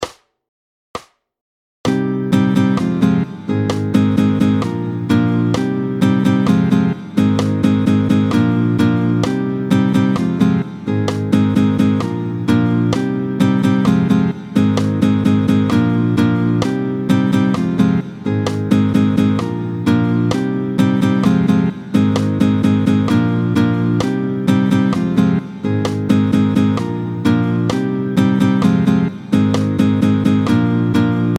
Vite, 2/2 tempo 130